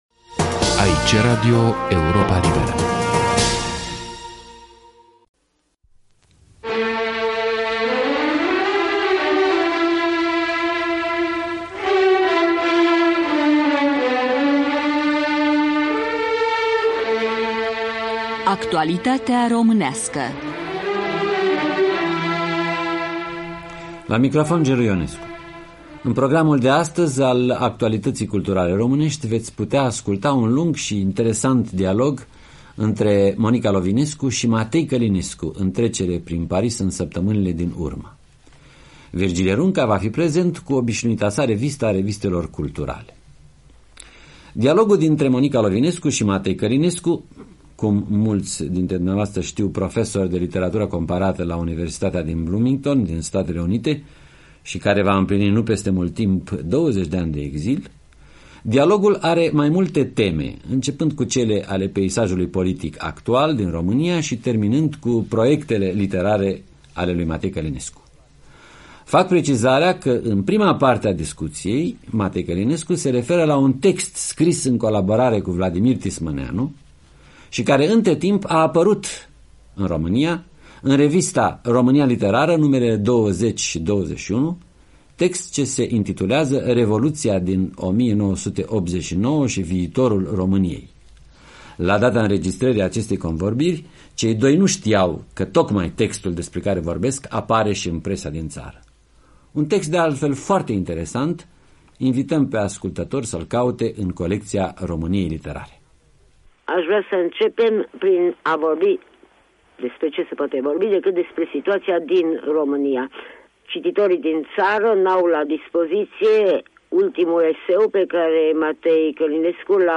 Monica Lovinescu în dialog cu Matei Călinescu